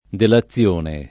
delazione [ dela ZZL1 ne ]